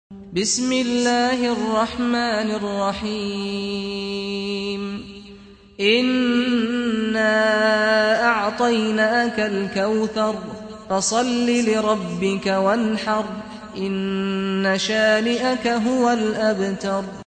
سُورَةُ الكَوۡثَرِ بصوت الشيخ سعد الغامدي